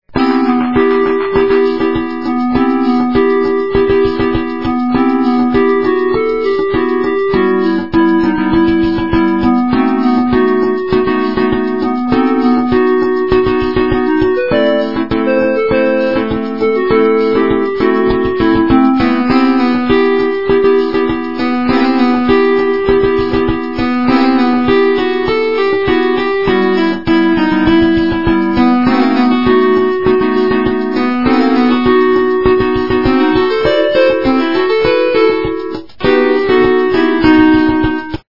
полифоническую мелодию